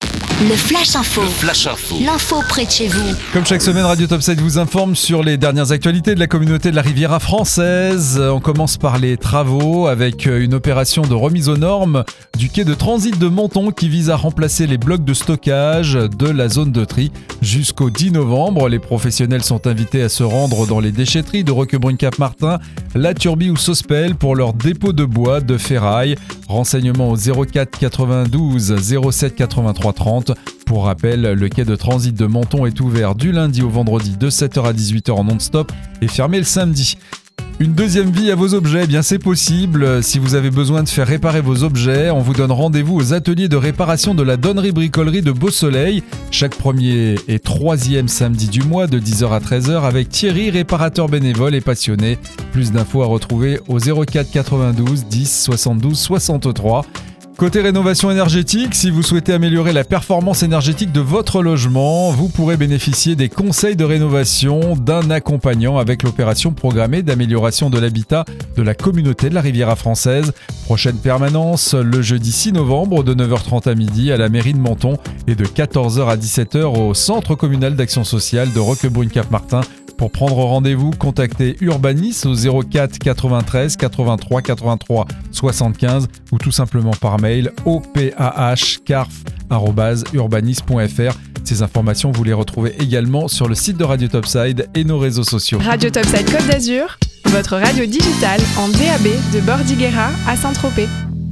C.A.R.F ACTU - FLASH INFO SEMAINE 44